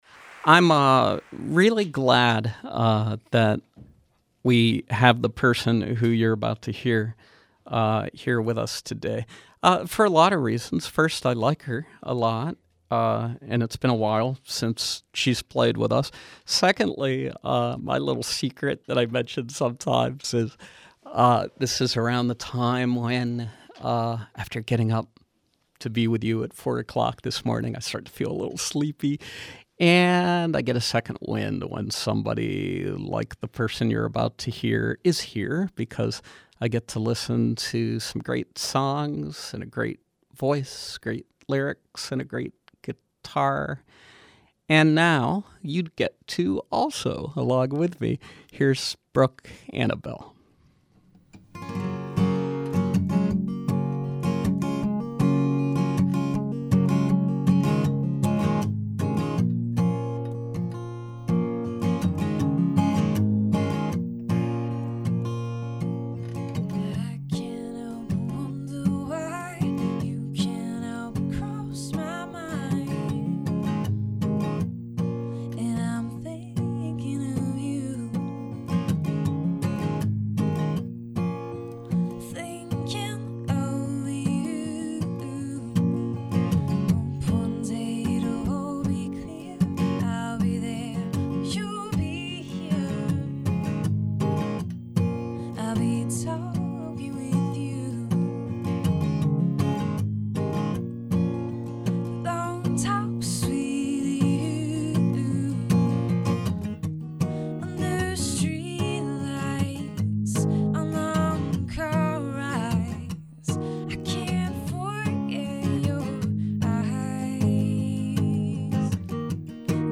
acoustic folk originals paired with earthy vocals